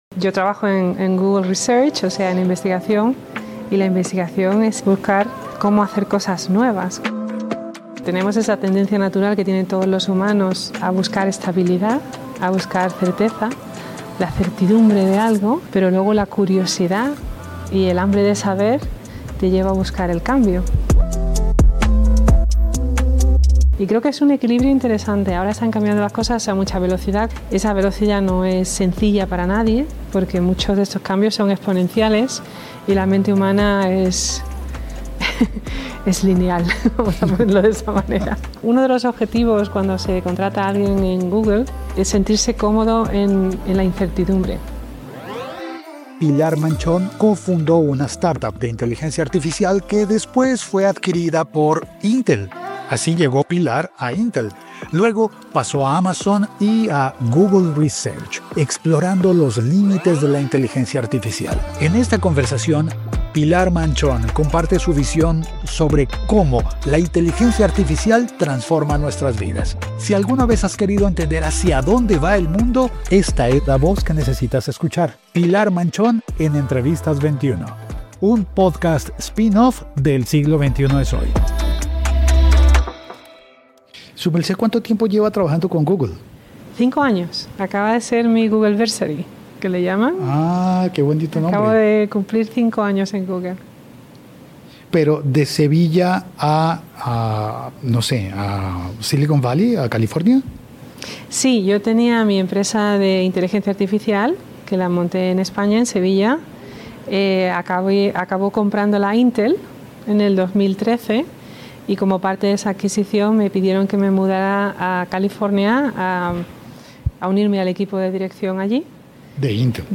Entrevistas del Archivo / ¿Puede la IA superar al cerebro humano?